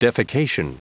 Prononciation du mot defecation en anglais (fichier audio)
Prononciation du mot : defecation